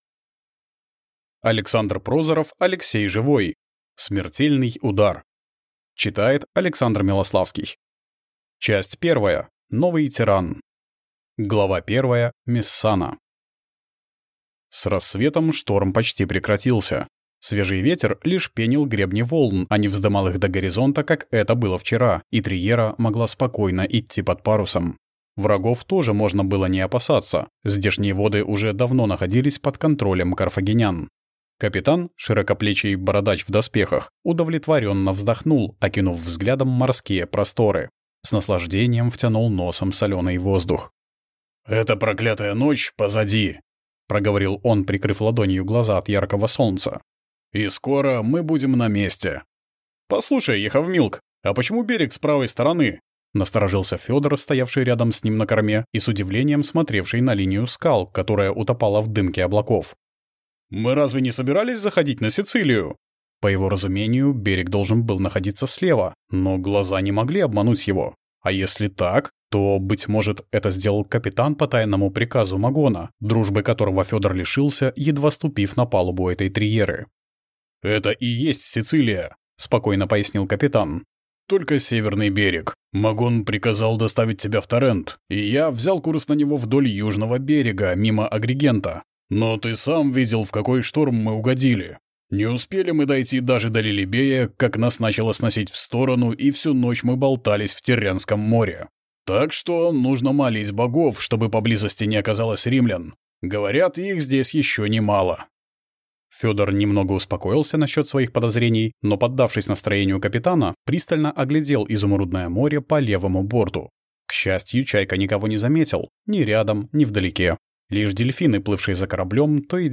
Аудиокнига Смертельный удар | Библиотека аудиокниг